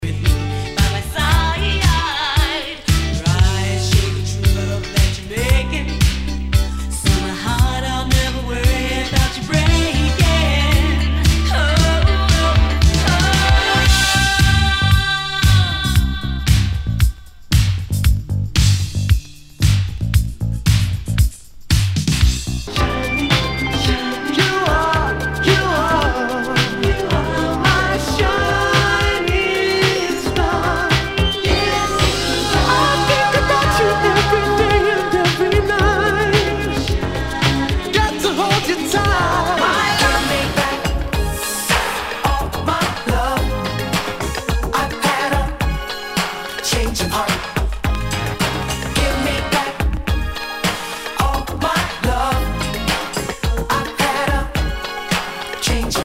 HOUSE/TECHNO/ELECTRO
ナイス！ダンス・クラシック！
盤に傷あり全体にチリノイズが入ります
[VG-] 傷や擦れが目立ち、大きめなノイズが出る箇所有り。